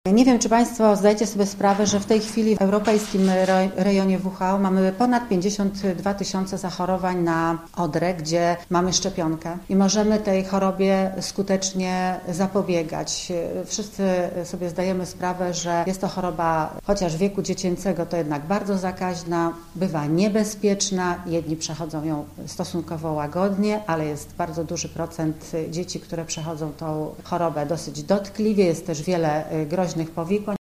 Szczepienia są pierwszym i najważniejszym ogniwem w całym procesie ochrony ludzkiego zdrowia przed chorobami zakaźnymi – przekonywali uczestnicy konferencji, która z okazji ogólnopolskiego dnia szczepień odbyła się w gorzowskim sanepidzie.